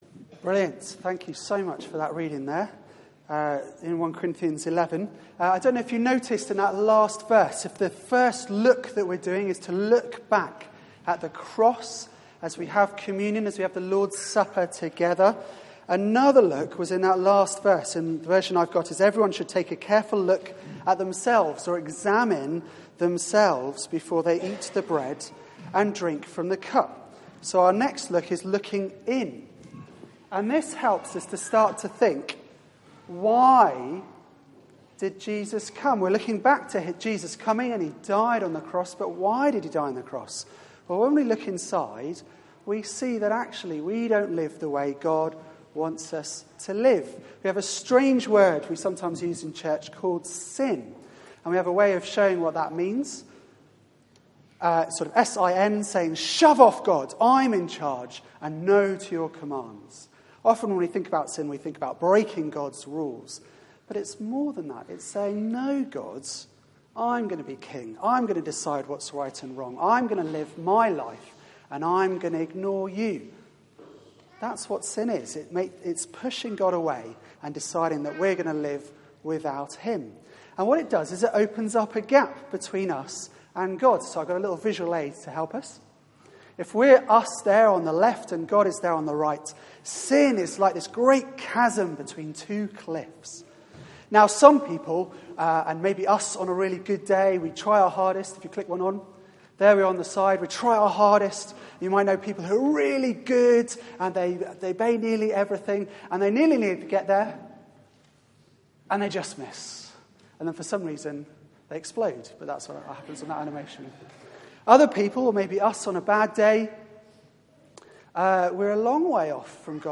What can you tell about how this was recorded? Media for 4pm Service on Sun 08th Nov 2015 16:00 Speaker